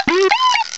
cry_not_bidoof.aif